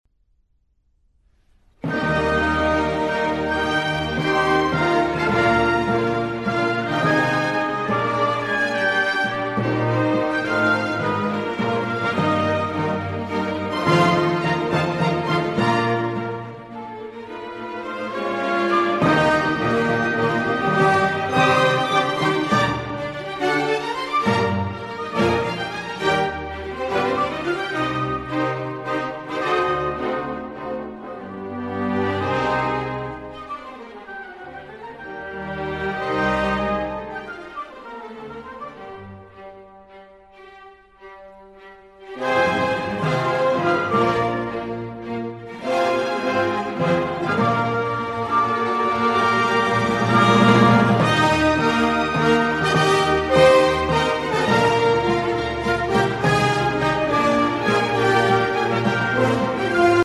Robert Schumann - Symphony No. 3 in E flat major, Op. 97